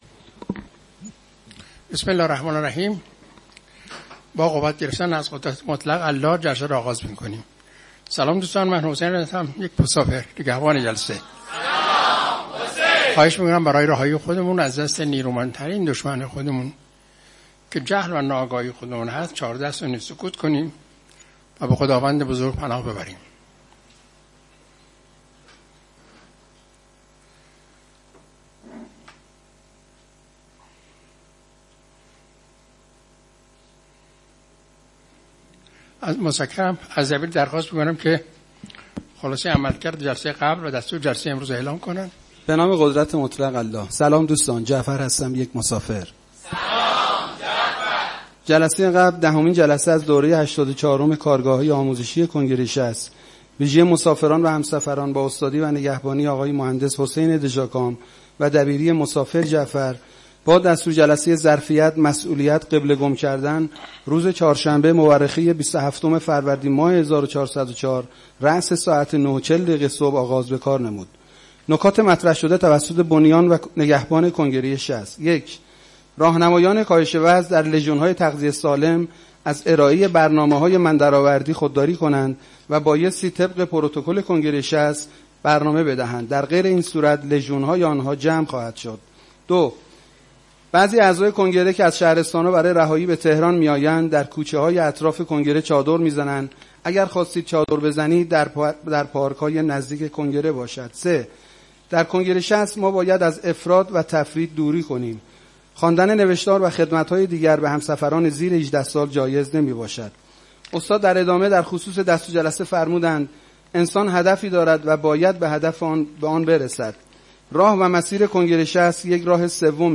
کارگاه آموزشی جهان‌بینی: وادی دوم و تأثیر آن روی من